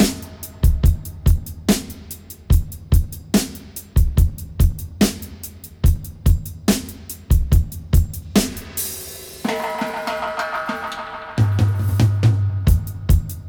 141-FX-01.wav